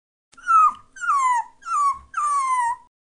Dog_Cry01.mp3